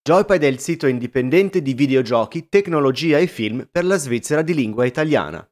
Per quanto riguarda il microfono, qua sotto trovate tre esempi, il primo usando il microfono delle Delta II, il secondo usando il leggendario Shure SMB7 e la terza il ROG Carnyx, microfono USB da stramer di ASUS.
Shure SM7B:
Carnyx-review-SM7B.mp3